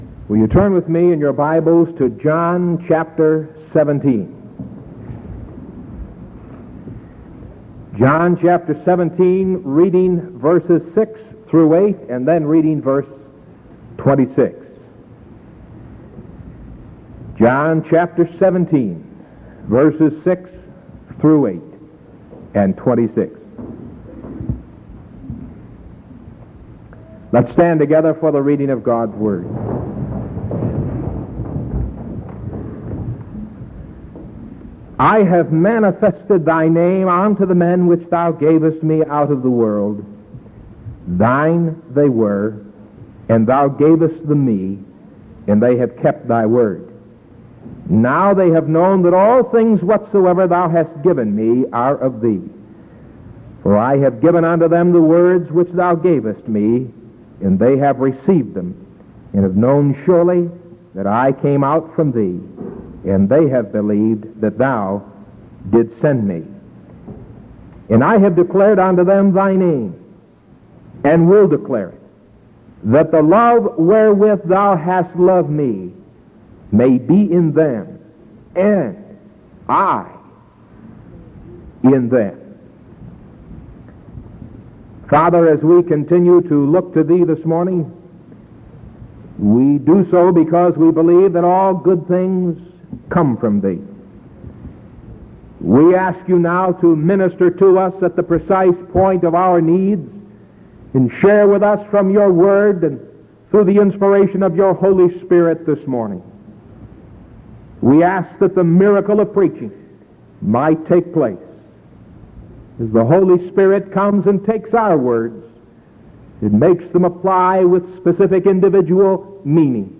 Sermon July 15th 1973 AM